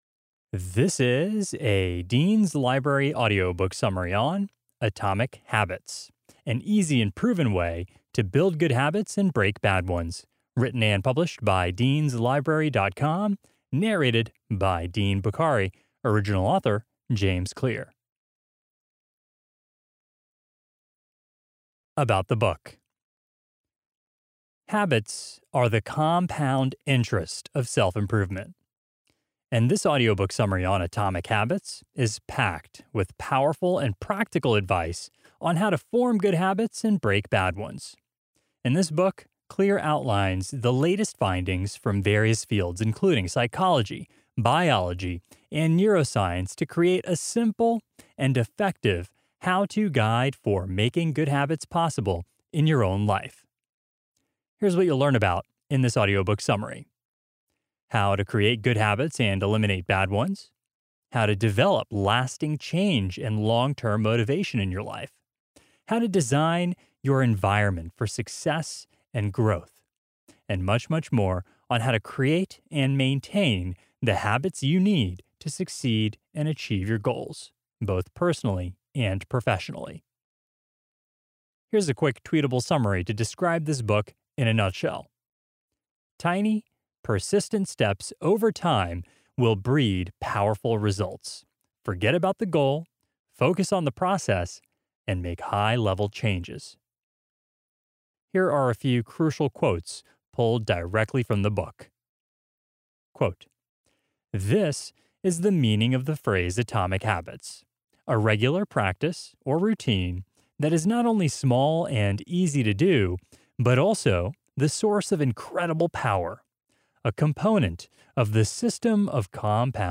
Audiobook Summary (MP3)